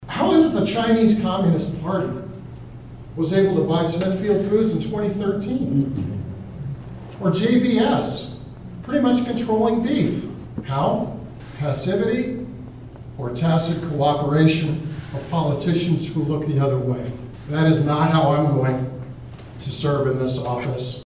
Republican House & Senate candidates speak in Atlantic Monday evening
(Atlantic, Iowa) – Cass County Republicans hosted a “Know Your Candidates” forum Monday evening, at the Cass County Community Center in Atlantic.